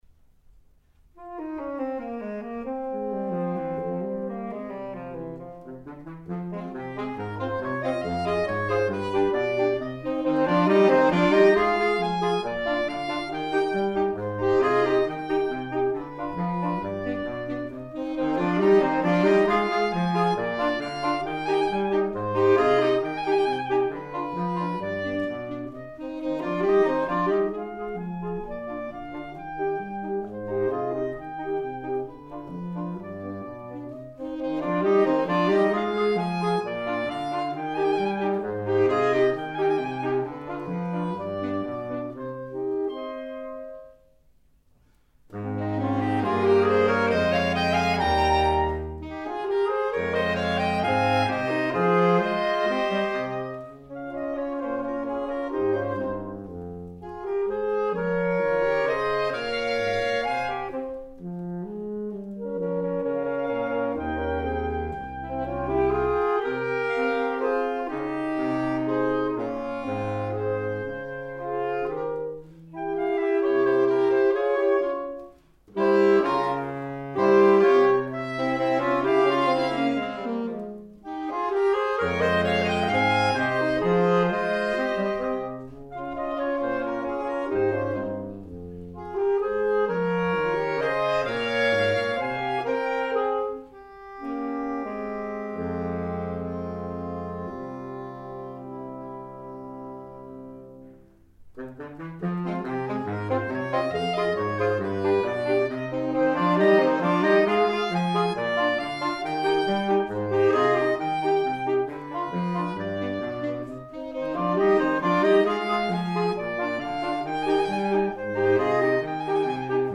Répertoire pour Saxophone